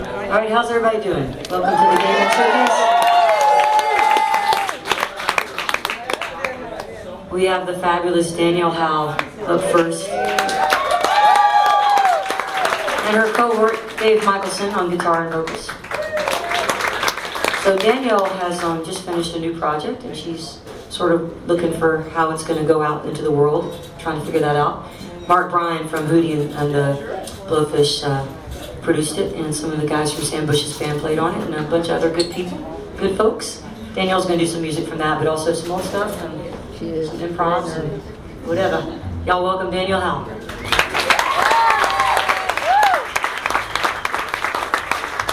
lifeblood: bootlegs: 2004-03-17: momo's - austin, texas (sxsw daemon records artist showcase) (alternate recording)